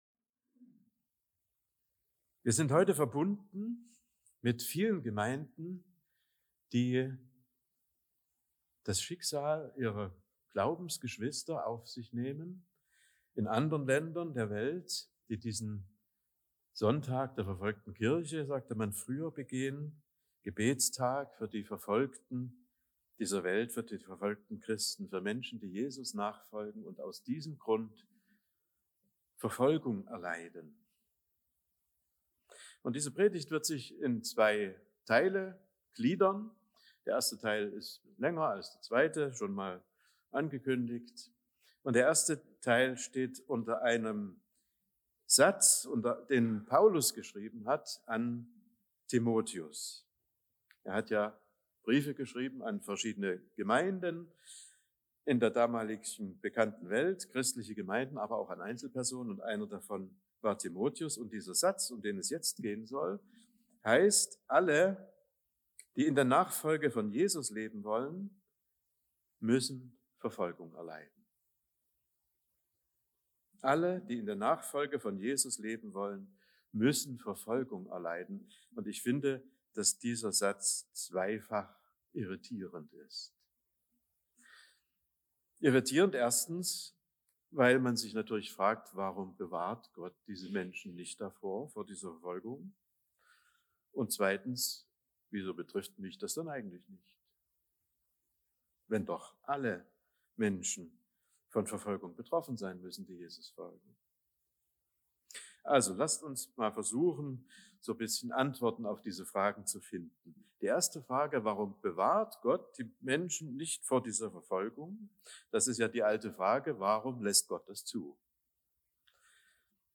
weltweiter-gebetstag-fuer-verfolgte-christen-2024